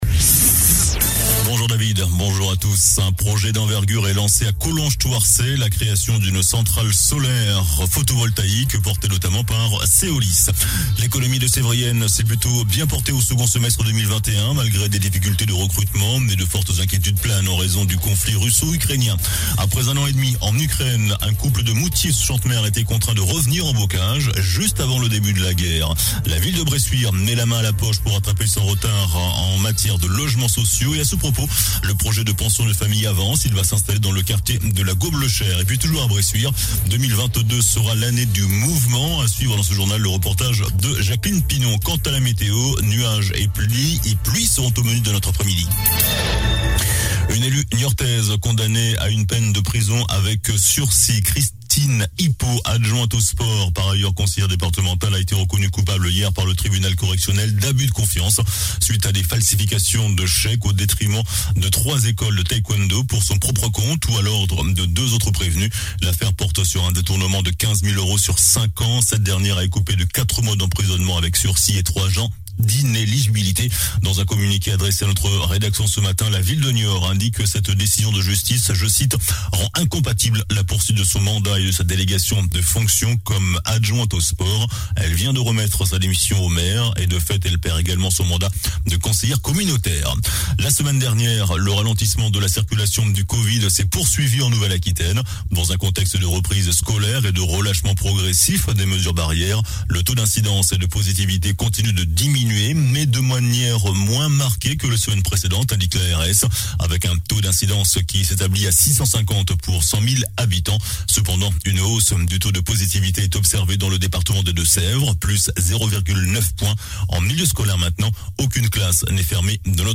JOURNAL DU VENDREDI 11 MARS ( MIDI )